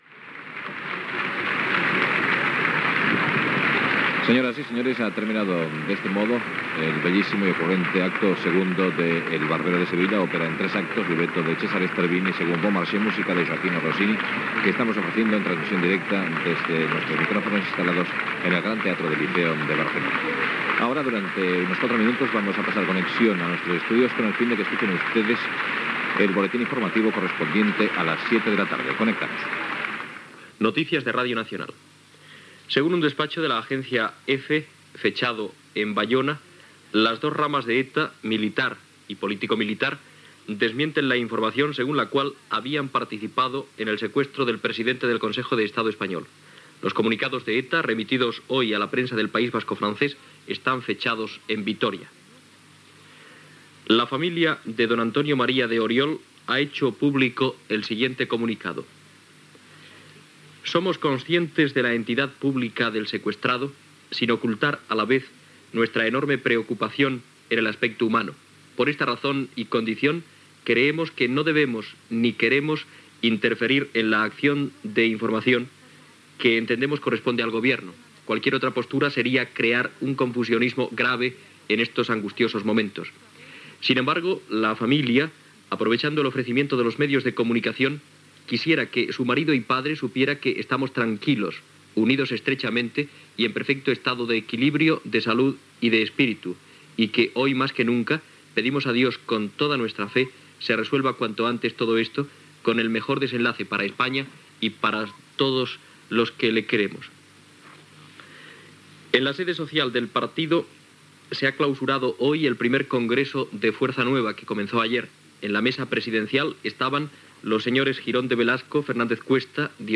Repressa de la retransmissió des del Gran Teatre del Liceu de Barcelona.